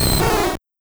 Cri de Minidraco dans Pokémon Or et Argent.